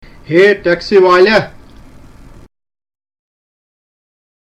A conversation on visiting the mosque at Hazratbal, ten miles from downtown Srinagar.